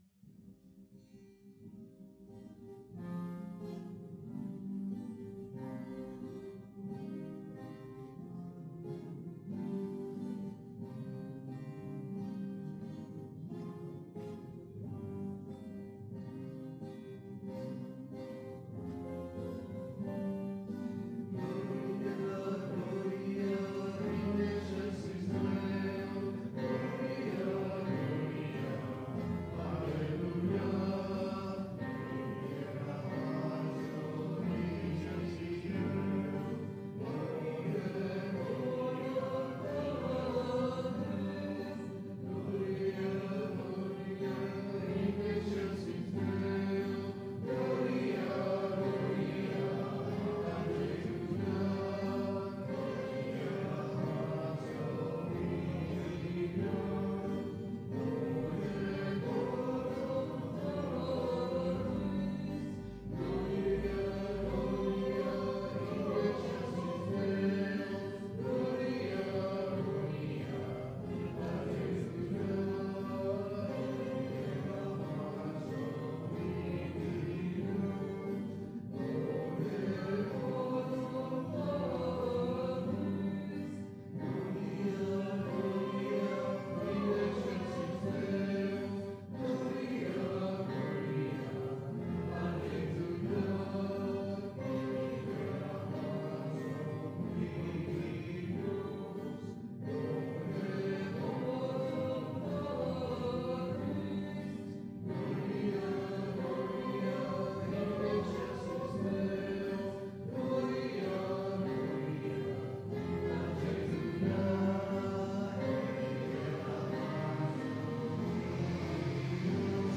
Pregària de Taizé a Mataró... des de febrer de 2001
Parròquia de la Mare de Déu de l'Esperança - Diumenge 31 de març de 2019